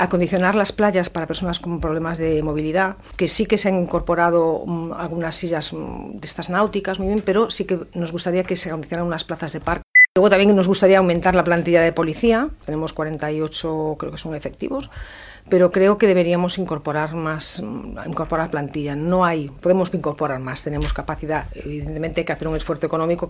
“No posarem pals a les rodes si hi ha propostes interessants per a Calella”, ha dit a l’entrevista política de Ràdio Calella TV d’aquesta setmana, quan ha recordat la predisposició de l’executiu de Marc Buch d’incorporar iniciatives de l’oposició.